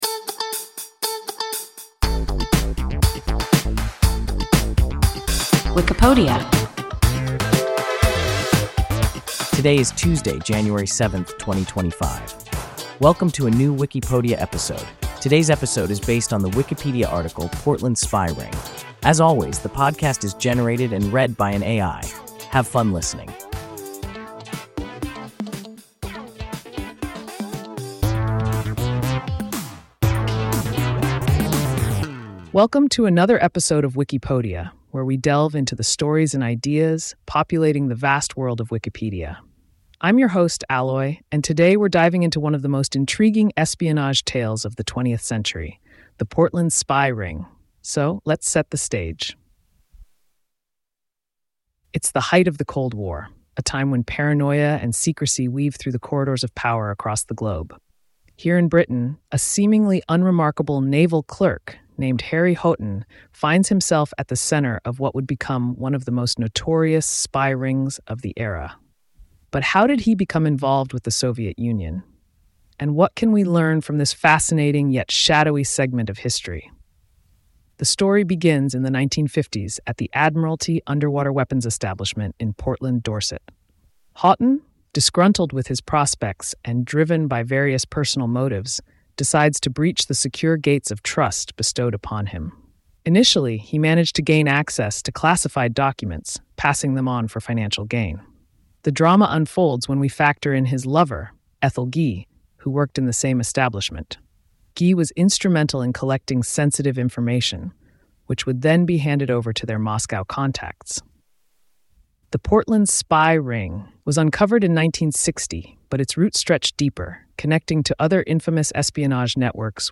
Portland spy ring – WIKIPODIA – ein KI Podcast